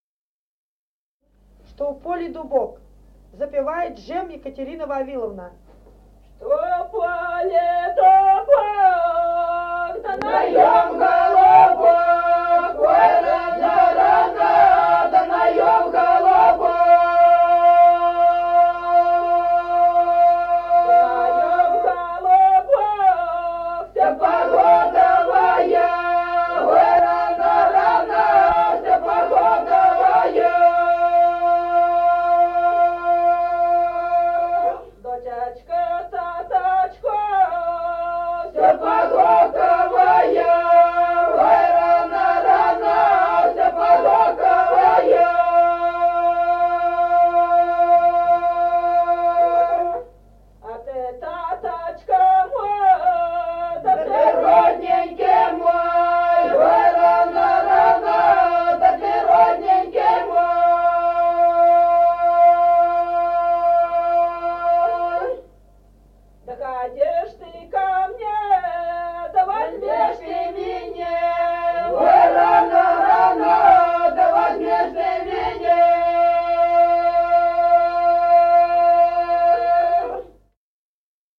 Песни села Остроглядово.